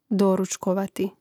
dòručkovati doručkovati gl. dvov. prijel./neprijel.